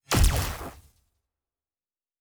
Sci-Fi Sounds / Weapons
Weapon 13 Shoot 2.wav